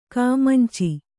♪ kāmanci